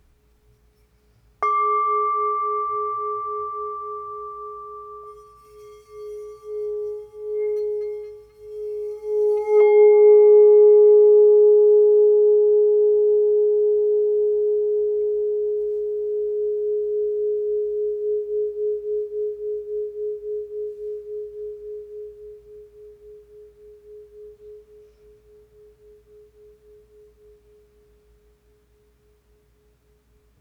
G Note 6.5″ Singing Bowl – Gopali Imports